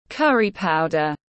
Bột cà ri tiếng anh gọi là curry powder, phiên âm tiếng anh đọc là /ˈkʌr.i ˌpaʊ.dər/
Curry powder /ˈkʌr.i ˌpaʊ.dər/